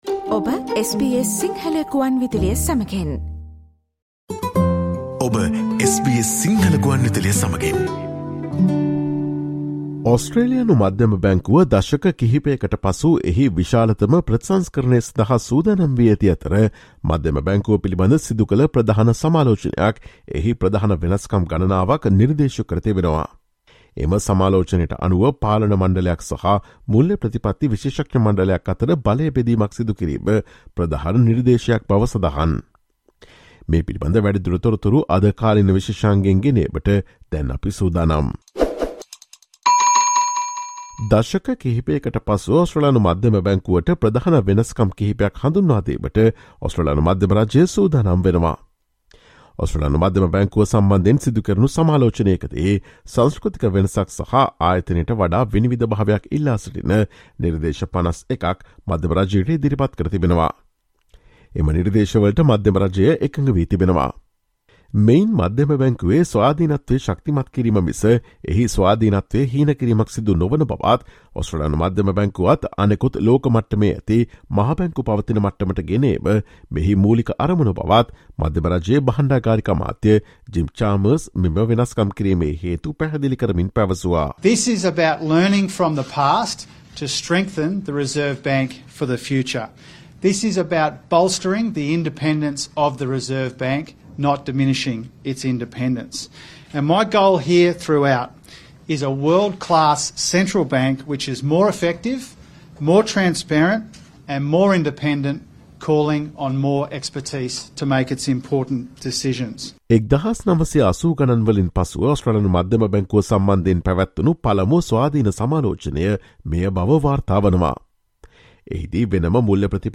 ඔස්ට්‍රේලියානු මධ්‍යම බැංකුව දශක කිහිපයකට පසු එහි විශාලතම ප්‍රතිසංස්කරණය සඳහා සූදානම් වී ඇති අතර, මධ්‍යම බැංකුව පිළිබඳ සිදුකළ ප්‍රධාන සමාලෝචනයක් එහි ප්‍රධාන වෙනස්කම් ගණනාවක් නිර්දේශ කර තිබේ. එම සමාලෝචනයට අනුව පාලන මණ්ඩලයක් සහ මුල්‍ය ප්‍රතිපත්ති විශේෂඥ මණ්ඩලයක් අතර බලය බෙදීමක් සිදු කිරීම ප්‍රධාන නිර්දේශයක් බව සඳහන්. මේ පිළිබඳ තොරතුරු රැගත් අප්‍රේල් 21 වන දා සිකුරාදා ප්‍රචාරය වූ SBS සිංහල සේවයේ කාලීන තොරතුරු විශේෂාංගයට සවන්දෙන්න.